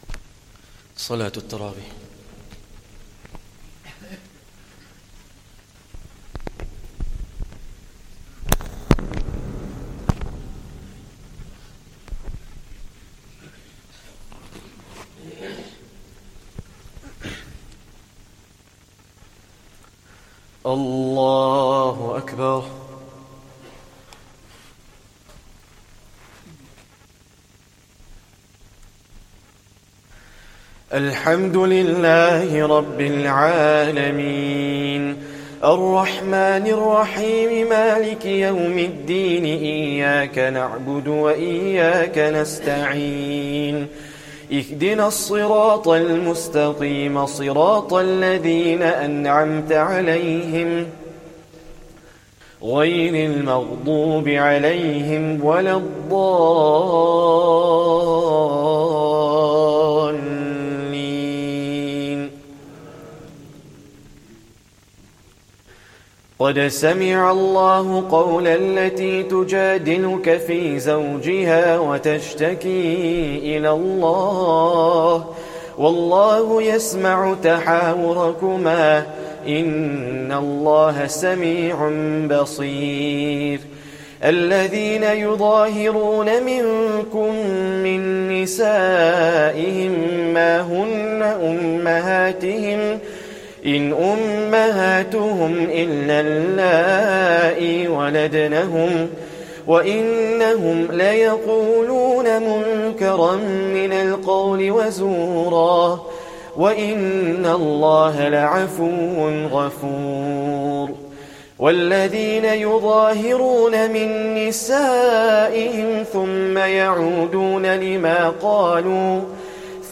Taraweeh Prayer 26th Ramadhan